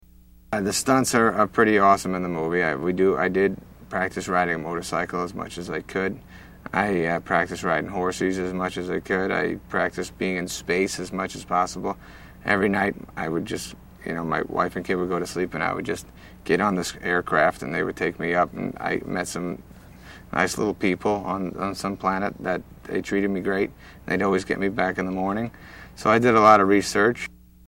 Adam Sandler interview